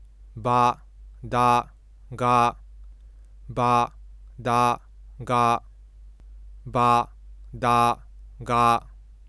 この音声は，/ba/, /da/, /ga/を複数回発音した発話から成っています。
F1は低めの周波数から上がり，F2は高めの周波数から下がっています。
badaga.wav